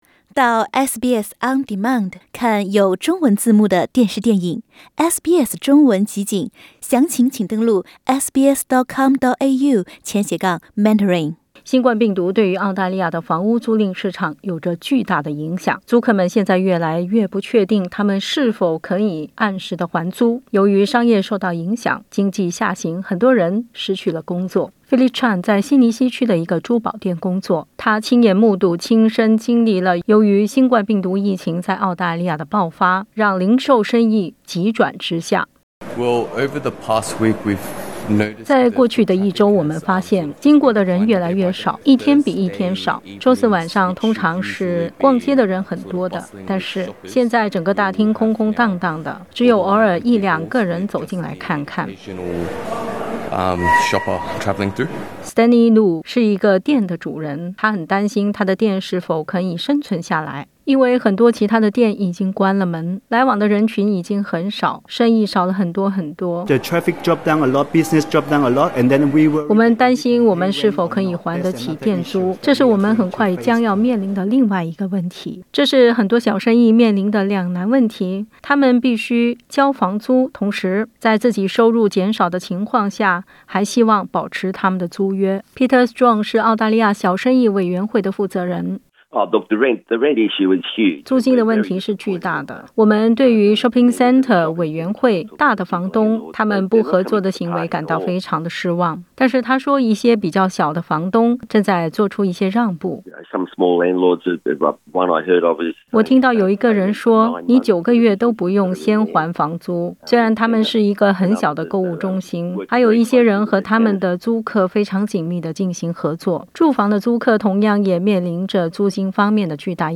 小生意业主委员会，大房东，各级政府将有什么建议和措施来帮助目前处于困境的租赁市场？点击上方图片收听录音报道。